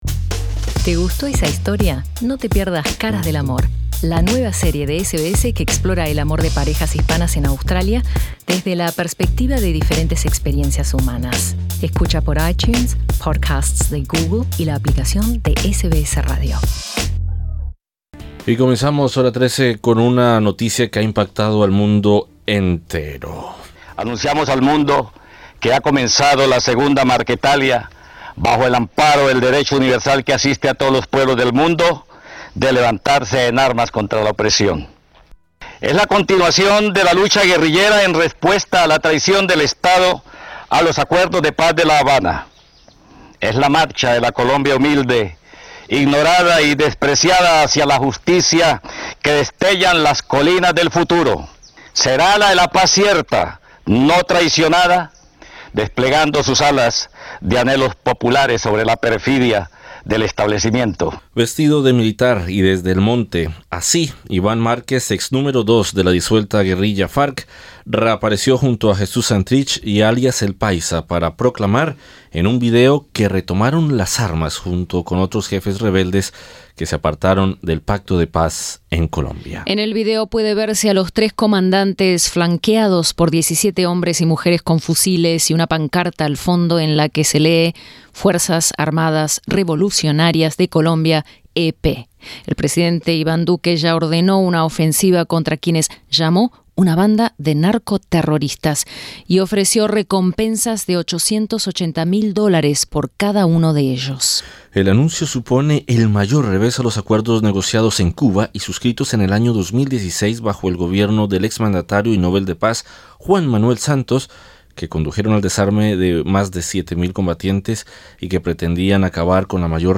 READ MORE Disidente de las FARC "Iván Márquez" anuncia que retoma las armas En entrevista con SBS Spanish el ex alto comisionado de la Paz y académico en Colombia, Daniel García Peña, dijo que el anuncio de Márquez representaba un duro revés para la paz en Colombia.